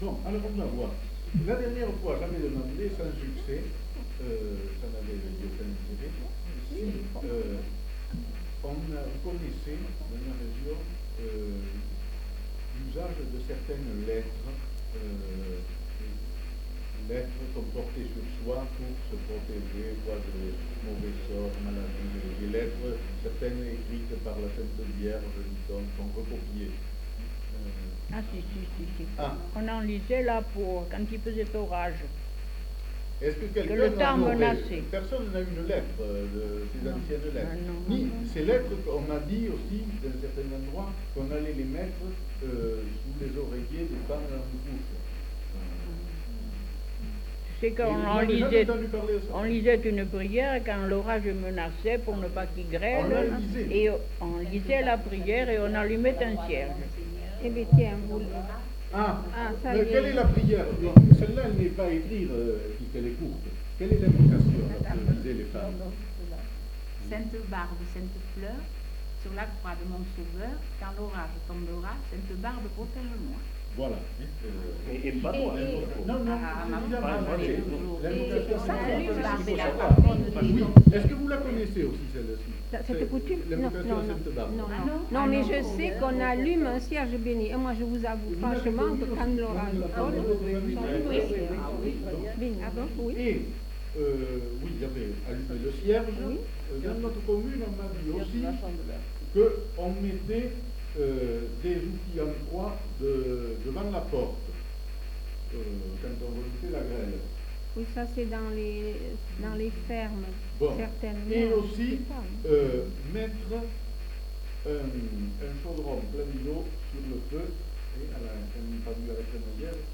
Lieu : Bazas
Genre : témoignage thématique
Type de voix : voix de femme Production du son : récité